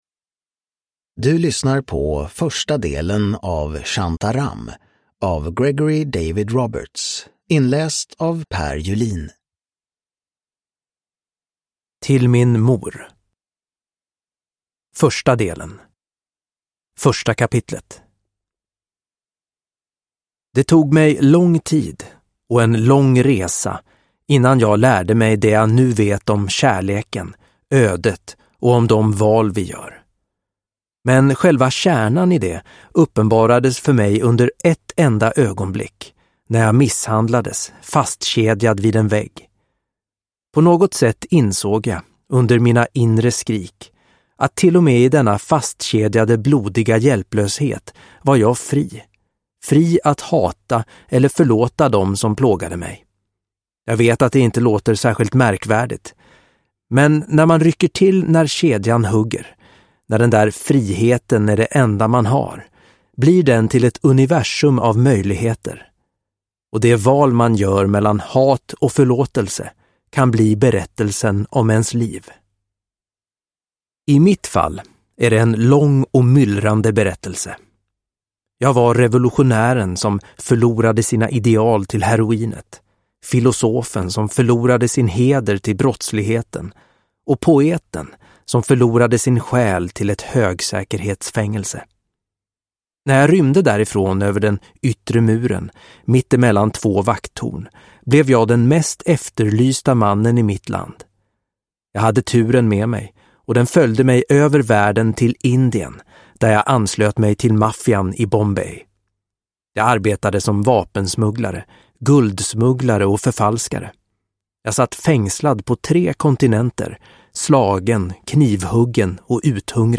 Shantaram. Del 1 – Ljudbok – Laddas ner